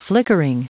Prononciation du mot flickering en anglais (fichier audio)
Prononciation du mot : flickering